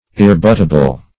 \ir`re*but"ta*ble\
irrebuttable.mp3